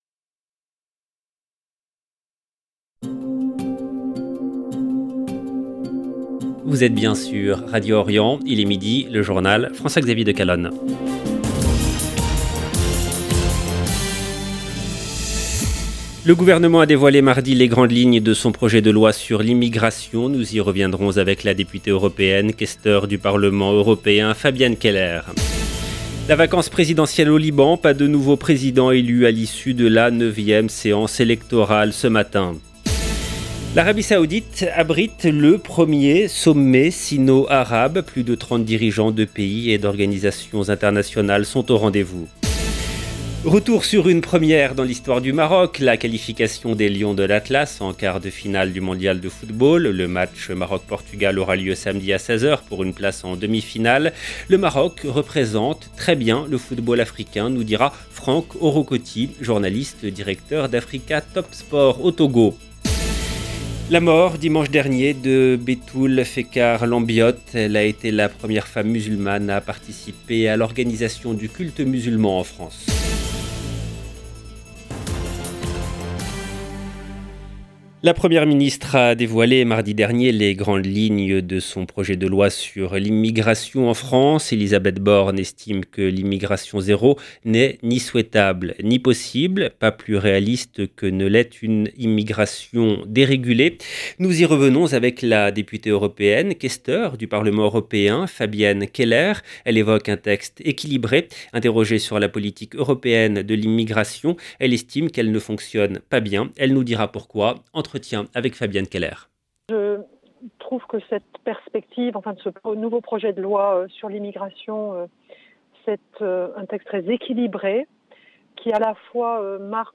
LE JOURNAL DE MIDI EN LANGUE FRANCAISE DU 8/12/22